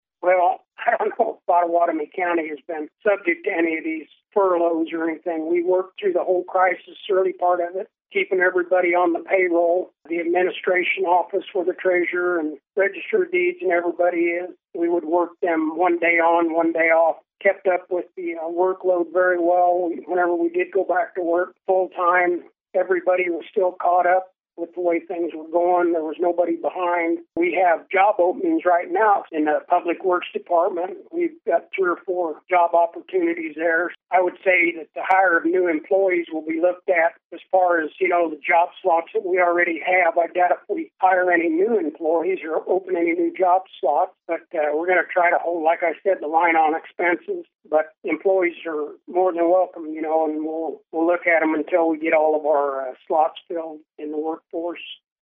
KMAN spoke with Weixelman about his motivation for running for reelection and his stances on various county issues.